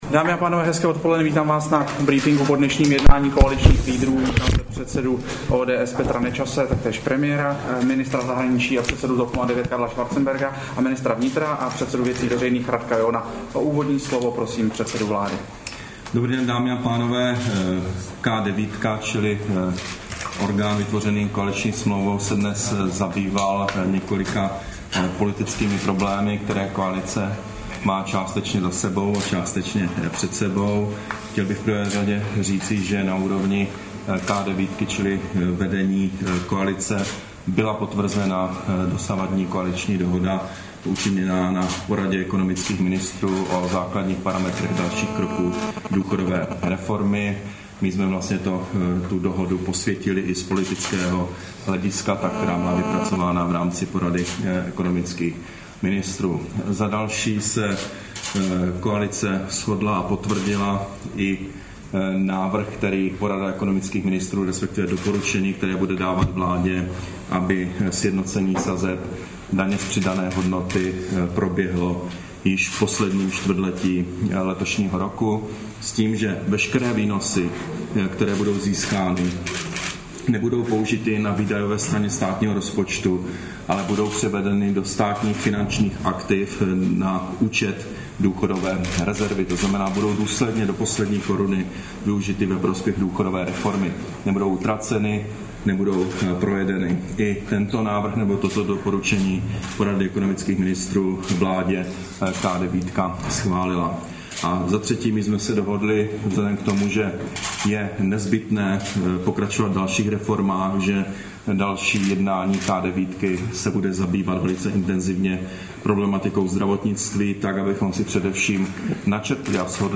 Brífink po jednání K9, 2. března 2011